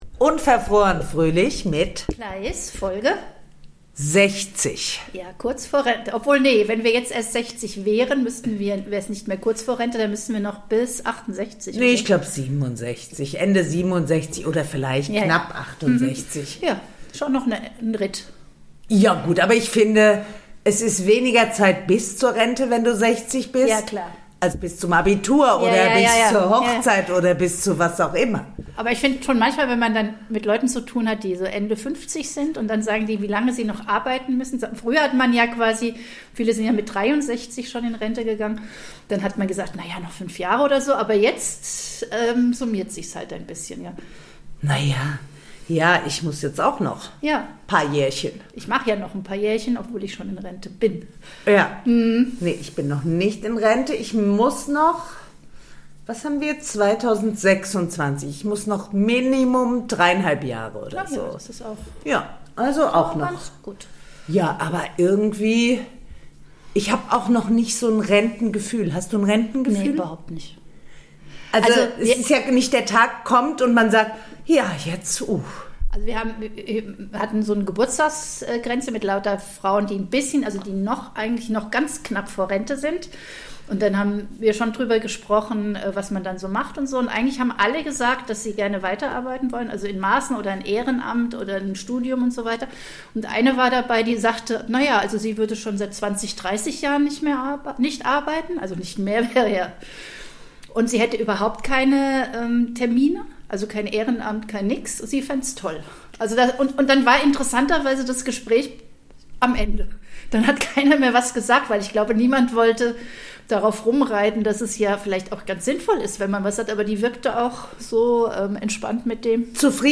reden die beiden Podcasterinnen über Osterpläne, Liebesdienste, herrliche Bücher und Empathie.